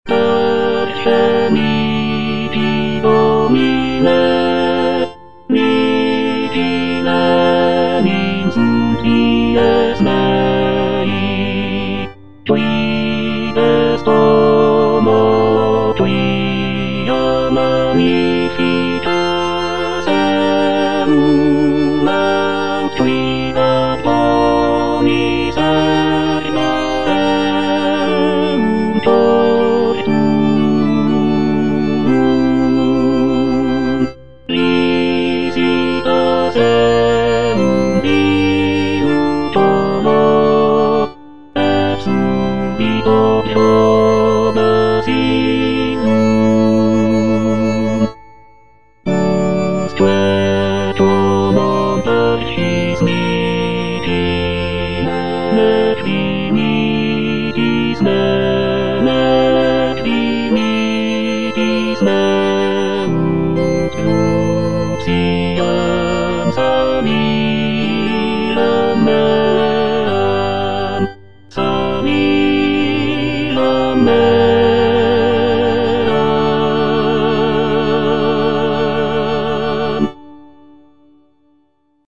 O. DE LASSUS - LECTIONES SACRAE NOVEM EX LIBRIS HIOB, LECTIO PRIMA LV676 Prima pars: Parce mihi Domine - Tenor (Emphasised voice and other voices) Ads stop:
The work is characterized by its rich harmonies, expressive melodies, and dramatic contrasts, creating a powerful and moving musical interpretation of the scripture.